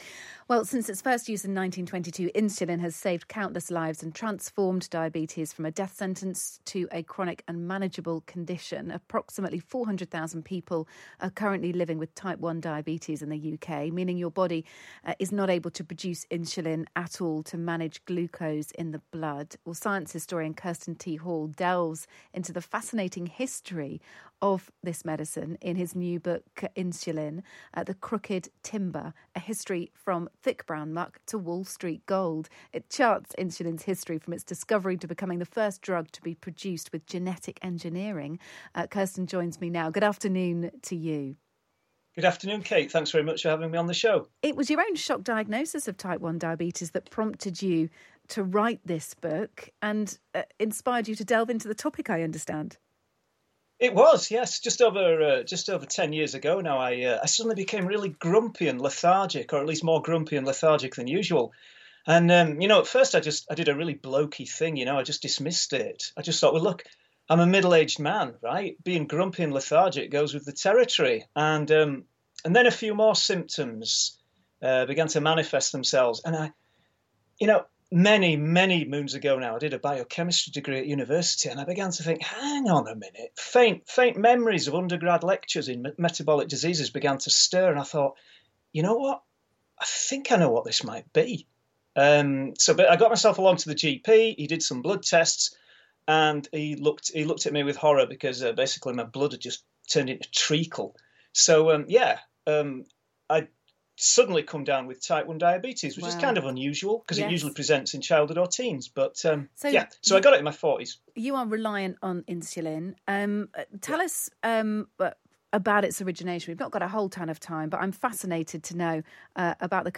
Insulin-Times-Radio.mp3